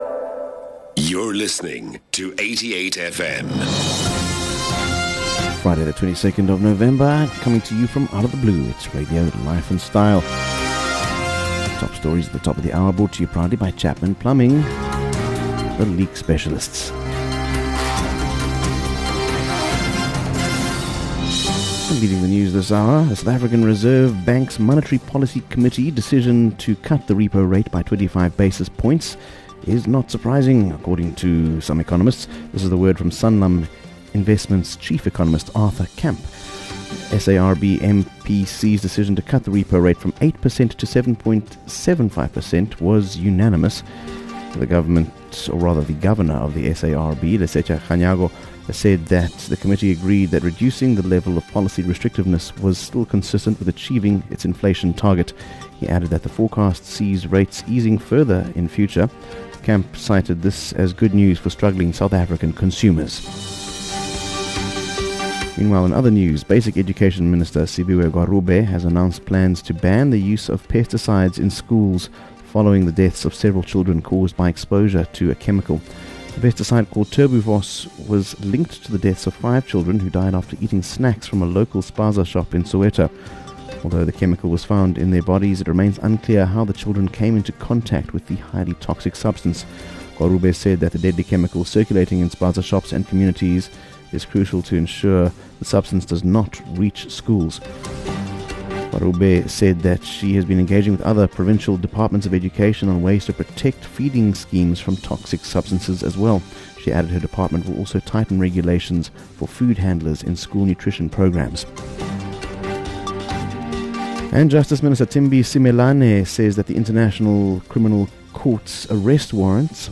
22 Nov News Bulletin - Friday 22 November 2024